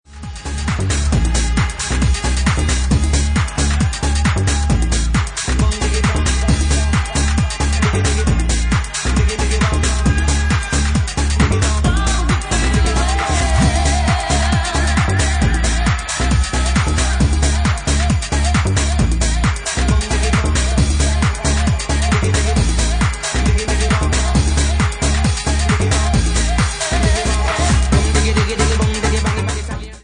Genre:Bassline House
Bassline House at 34 bpm